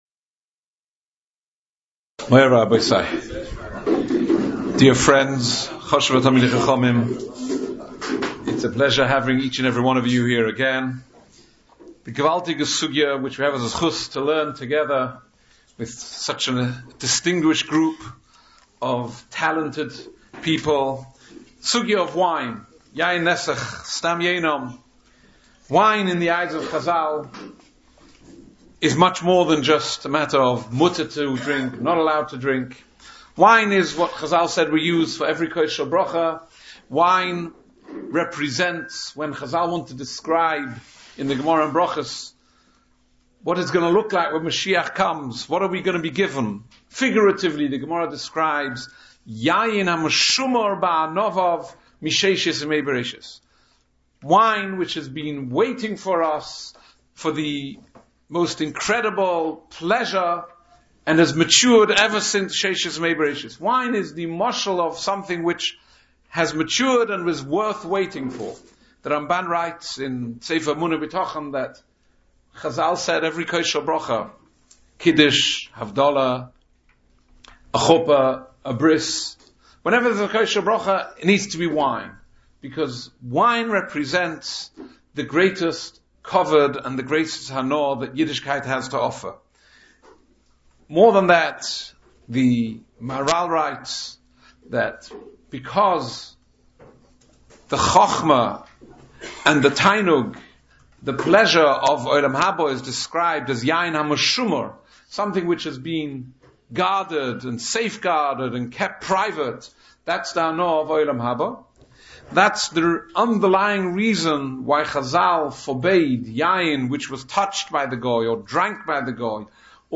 Shiurim
In Day 1, Yarchei Kallah - 2024